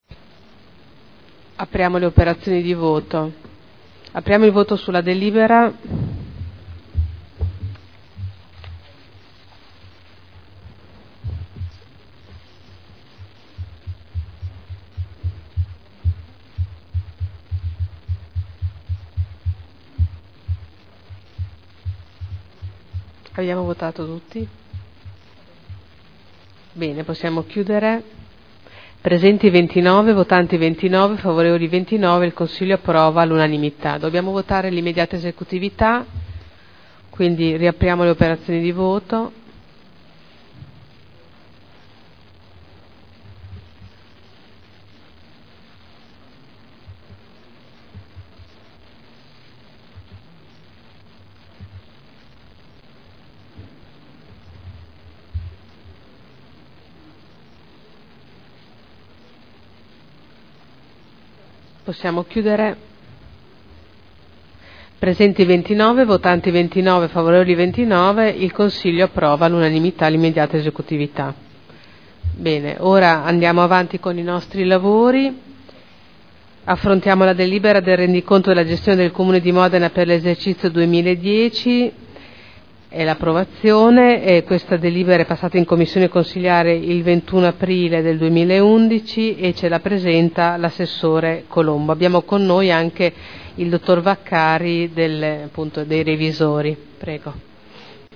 Presidente — Sito Audio Consiglio Comunale
Seduta del 28/04/2011.